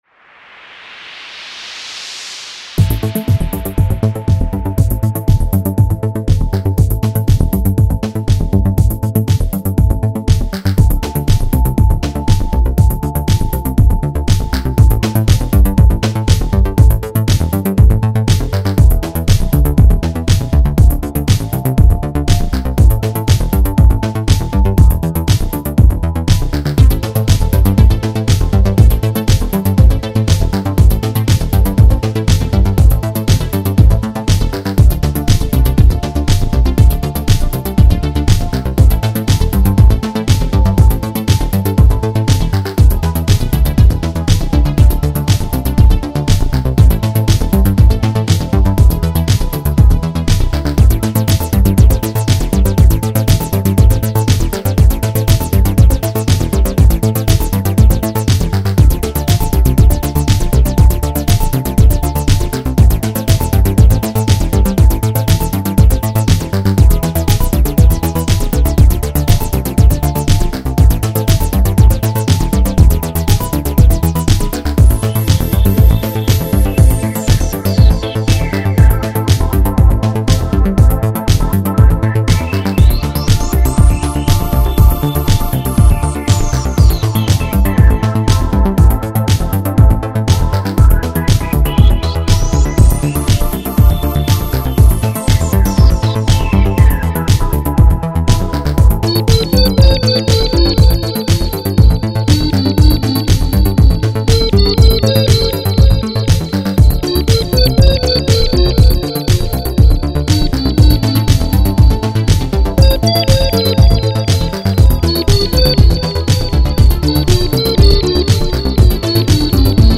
Це щось нагадує 80-ті р. Хоча звучить класно!
Симпатична мелодія.
А слів не треба smile